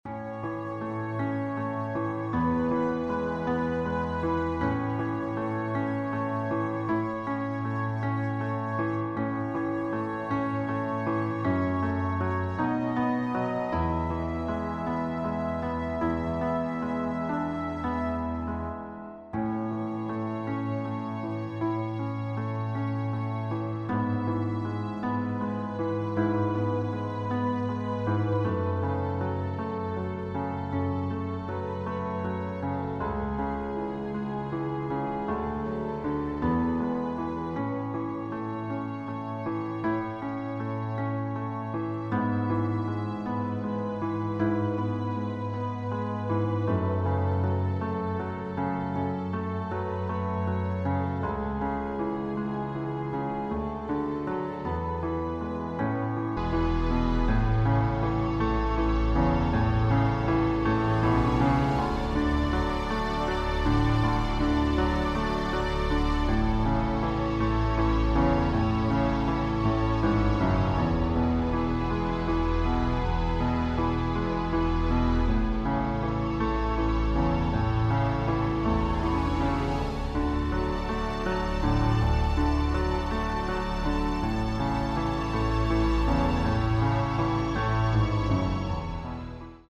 • Tonart: A Dur, C Dur, E Dur
• Art: Klavier Streicher Version
• Das Instrumental beinhaltet NICHT die Leadstimme
Klavier / Streicher